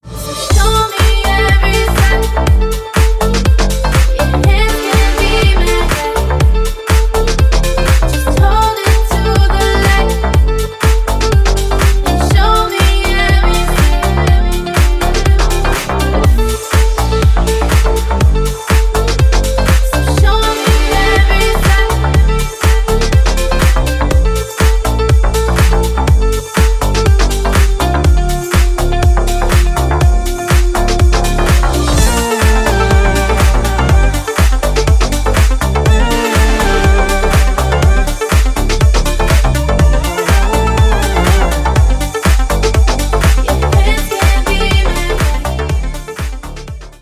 • Качество: 320, Stereo
красивые
женский вокал
deep house
dance
спокойные
house